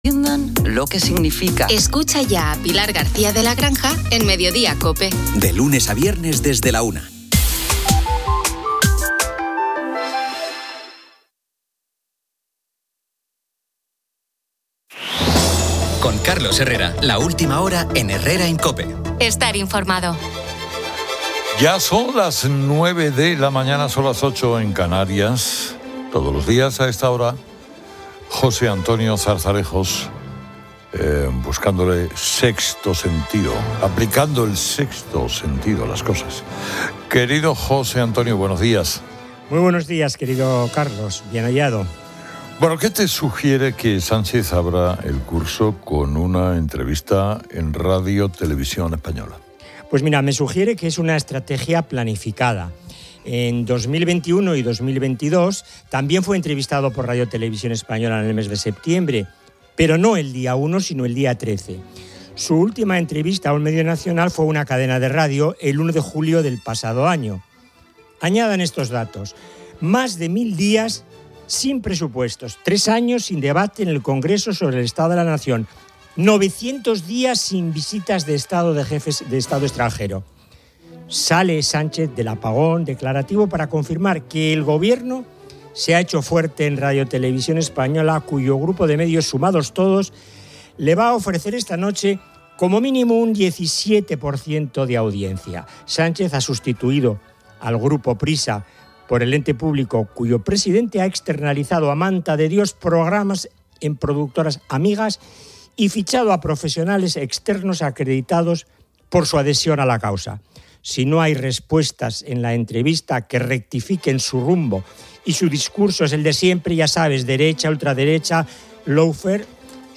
El audio presenta un resumen de noticias y debates.